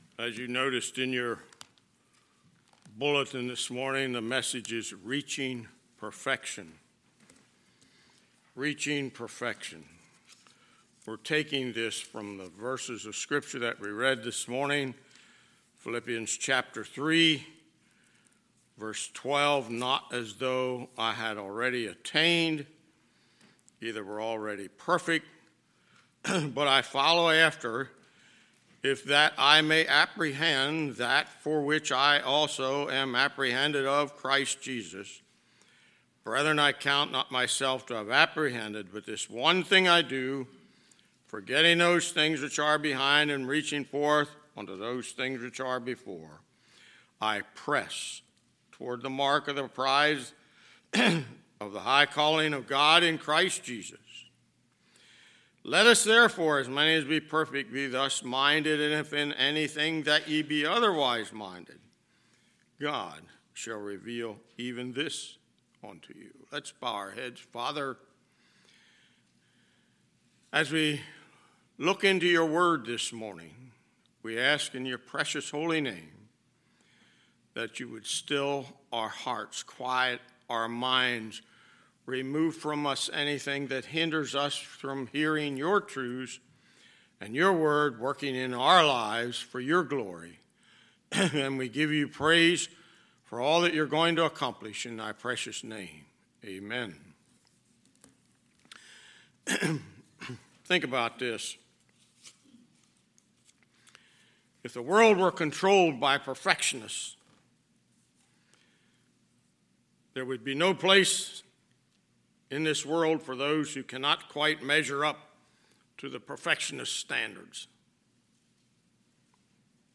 Sunday, January 30, 2022 – Sunday AM